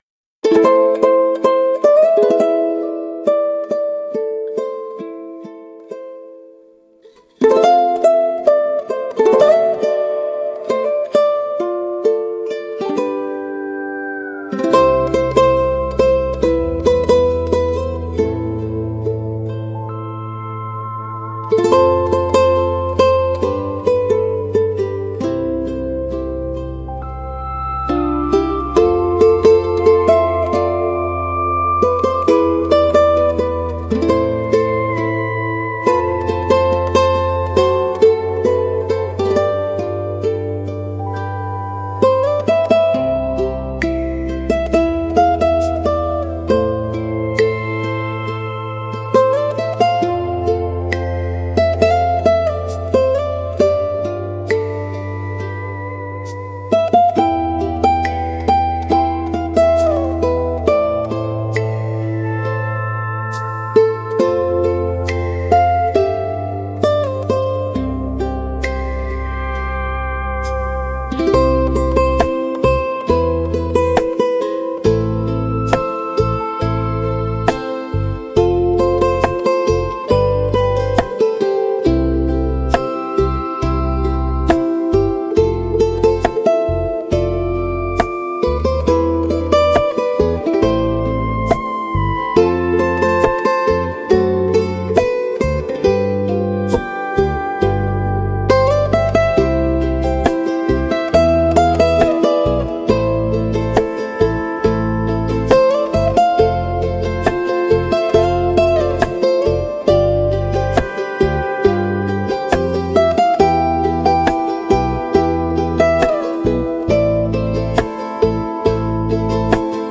聴く人にゆったりとした安らぎや心地よさを届ける、穏やかで親しみやすいジャンルです。